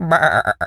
sheep_baa_bleat_07.wav